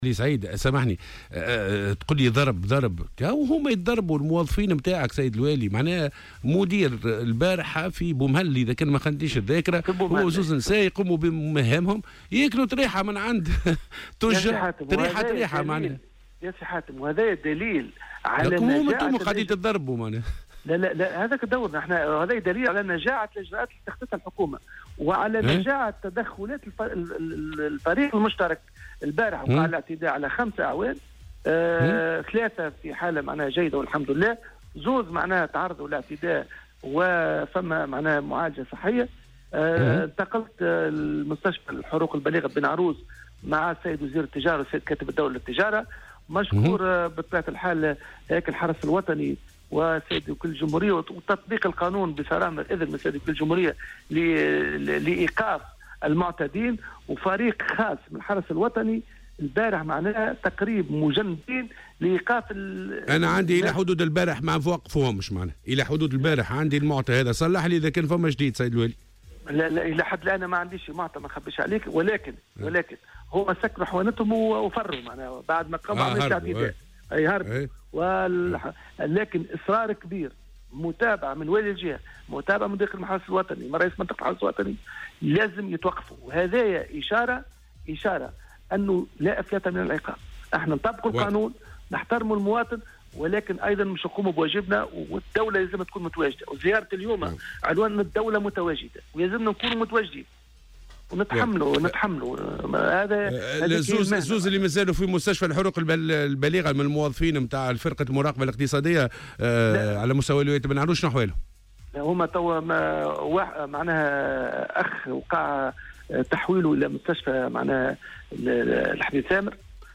وأضاف في مداخلة له اليوم في برنامج "صباح الورد" على "الجوهرة أف أم" أنه تحوّل رفقة وزير التجارة إلى مستشفى بن عروس للاطمئنان على صحة الأعوان، مؤكدا أنهم غادروا المستشفى باستثناء عون وحيد تم تحويله إلى مستشفى الحبيب ثامر.